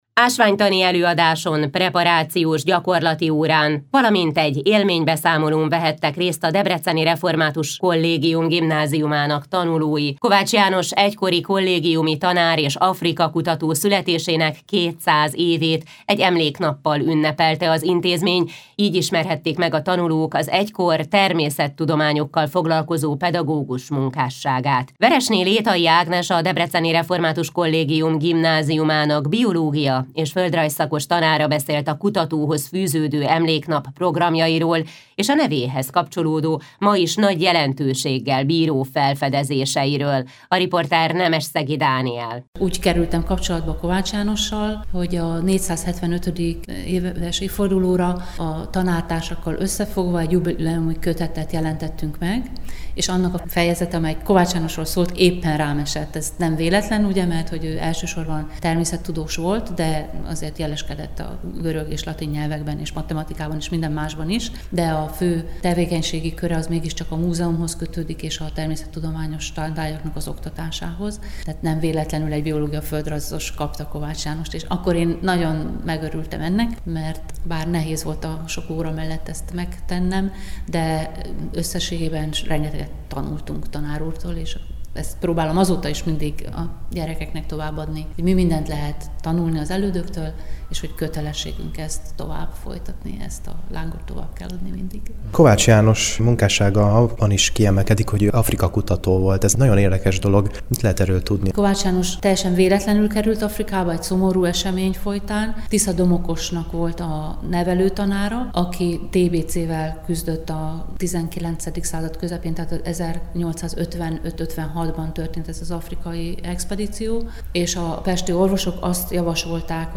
Letöltés Forrás: Európa Rádió